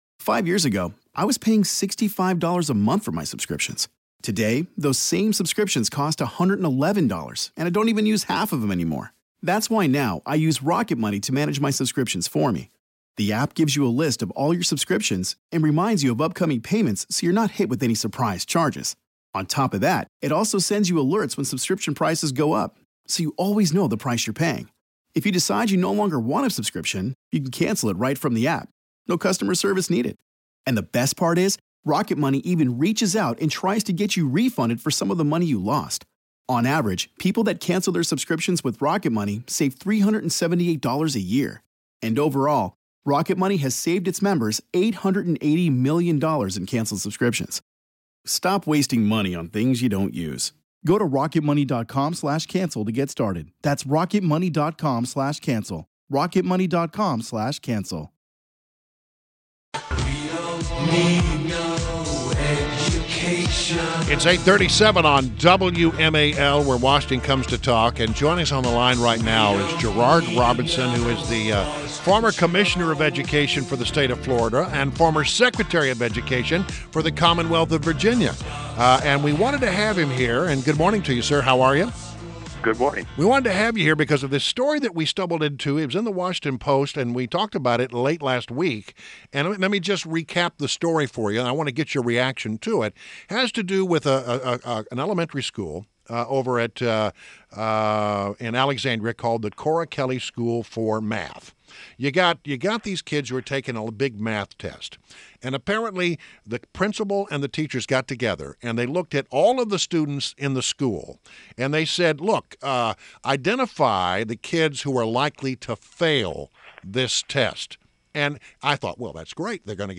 WMAL Interview - GERARD ROBINSON - 09.12.16